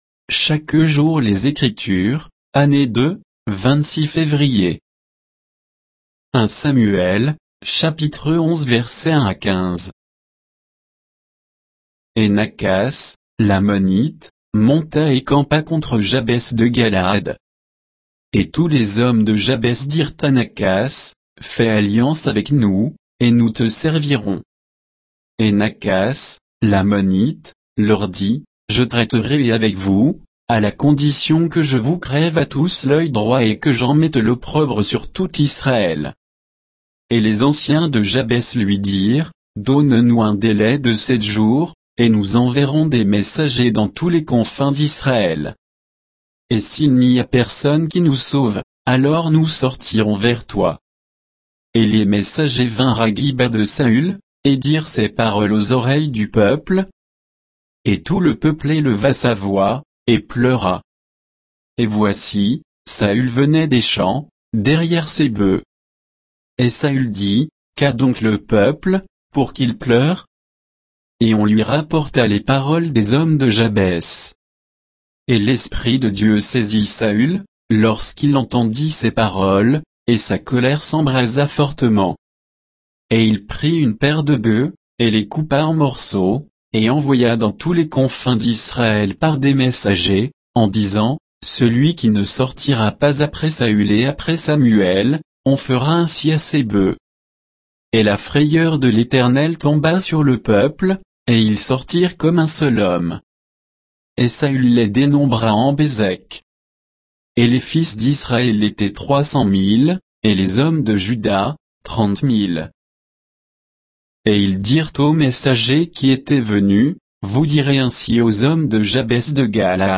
Méditation quoditienne de Chaque jour les Écritures sur 1 Samuel 11, 1 à 15